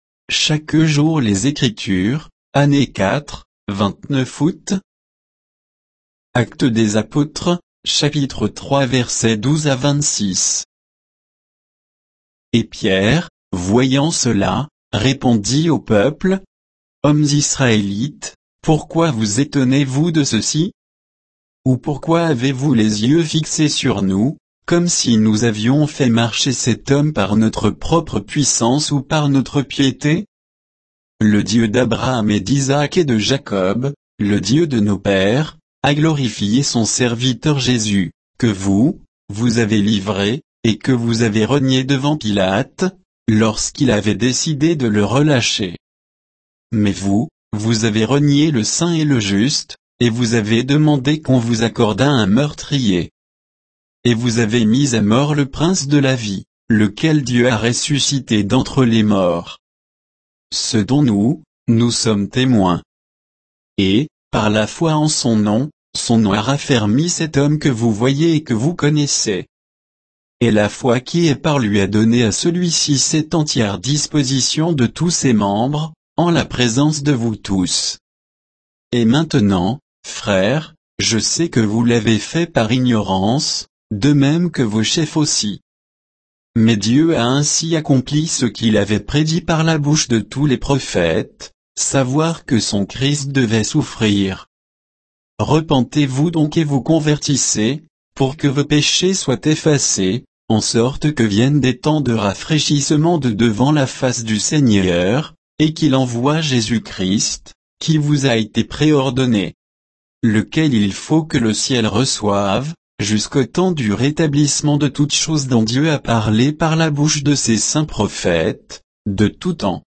Méditation quoditienne de Chaque jour les Écritures sur Actes 3, 12 à 26